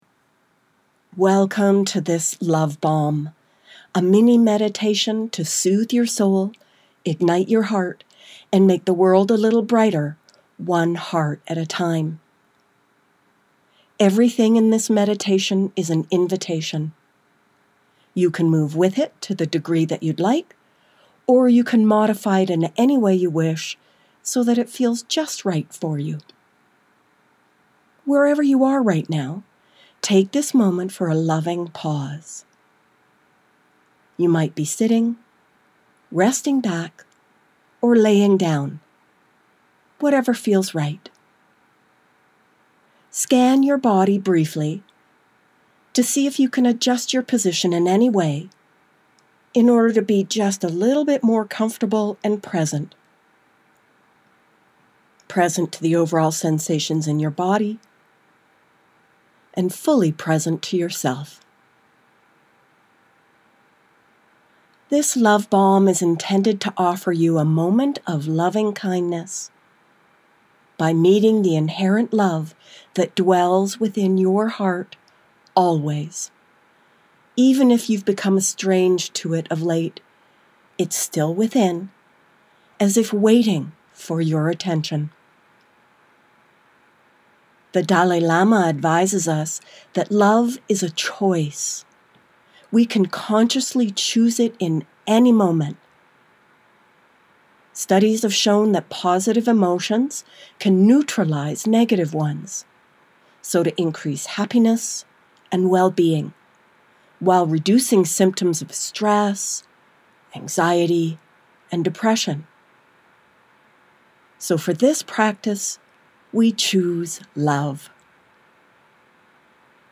LOVE BALMS – MEDITATIONS TO SOOTHE YOUR SOUL
Love-Balm-3-Loving-Kindness.mp3